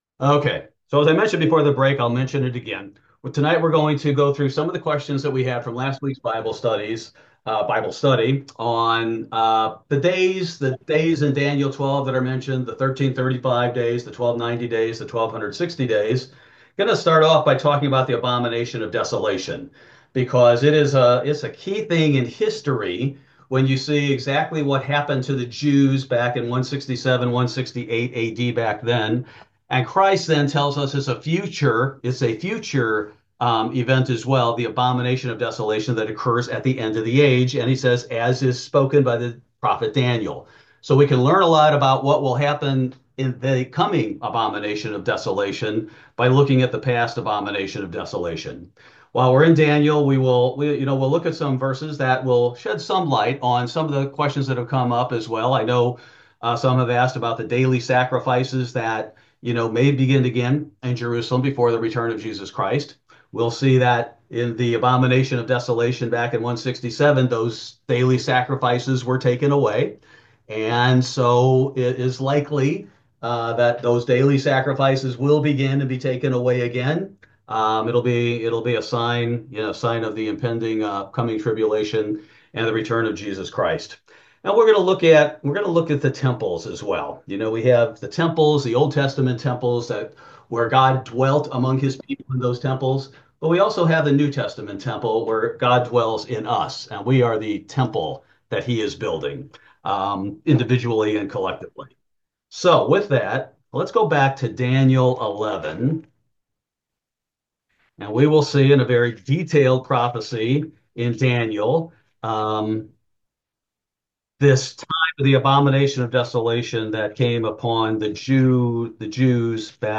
Bible Study: May 14, 2025